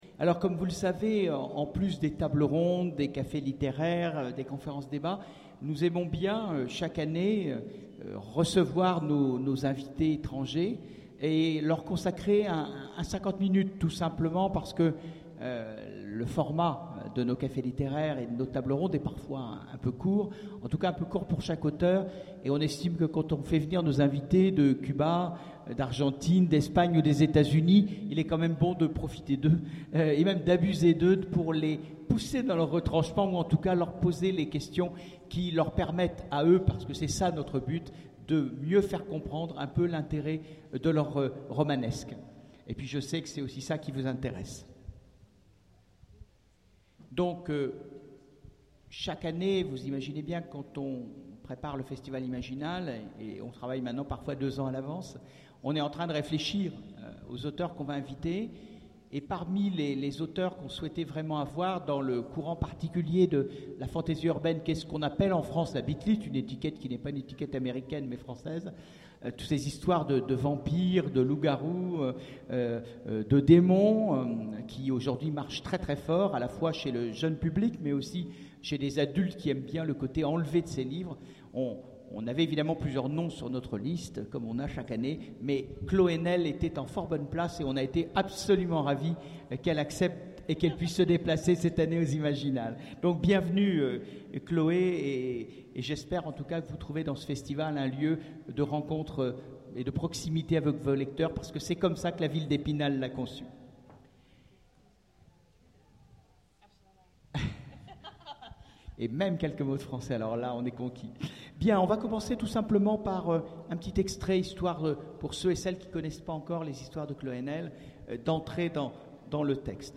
Imaginales 2014 : Entretien